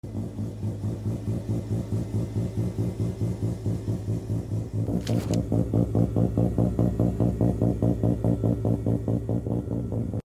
Mazda RX 7 ASMR Sound Effects Free Download